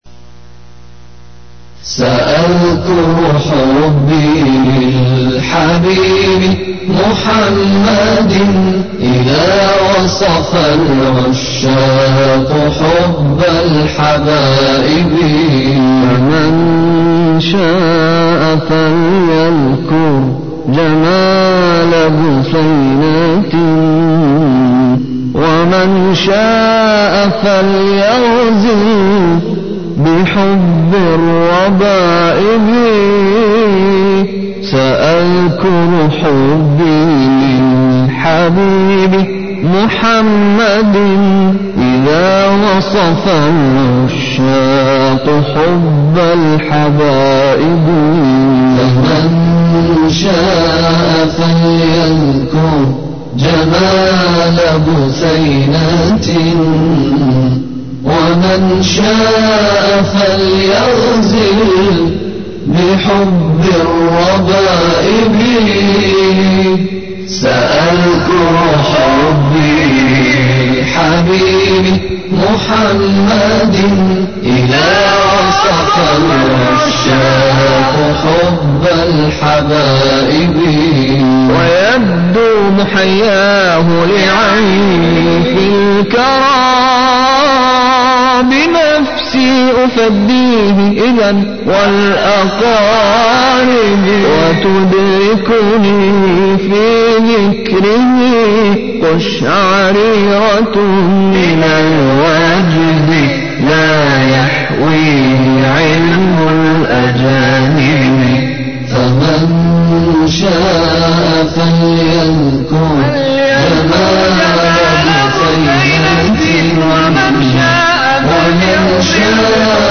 قد جمـــعت لكم اجمل ألبومات أناشيد
بجـودة ممتازة جدا